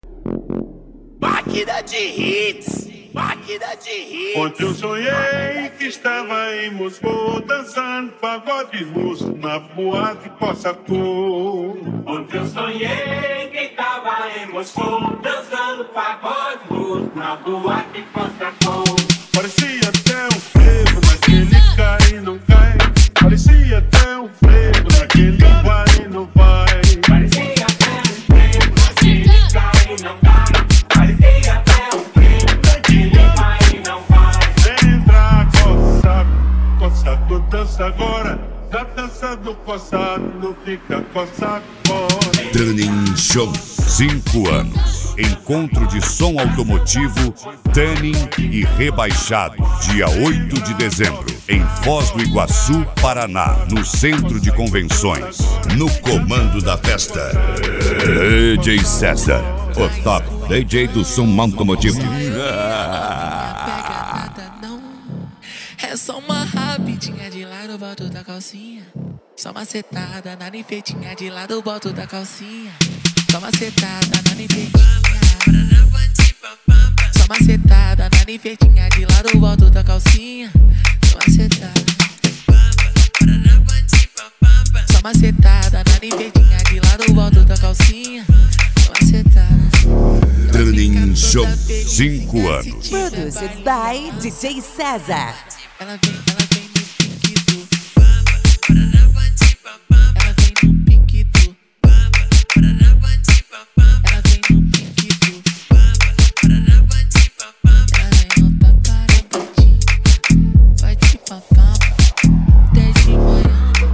Mega Funk